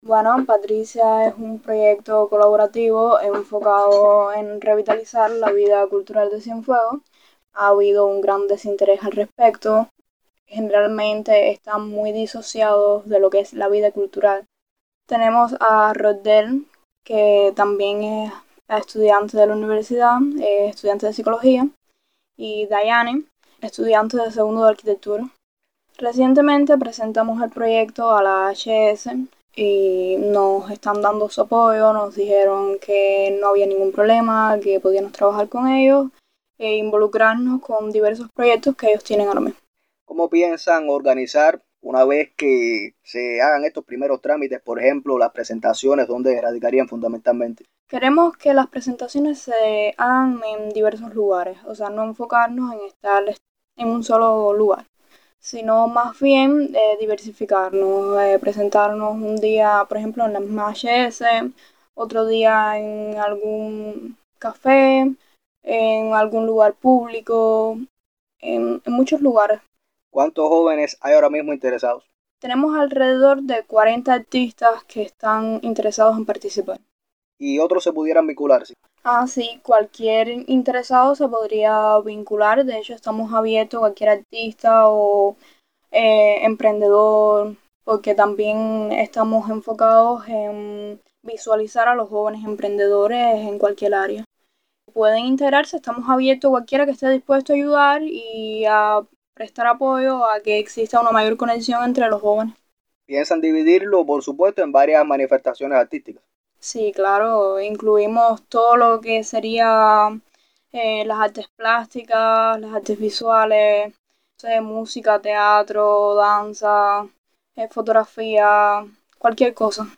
conversó con Radio Ciudad del Mar.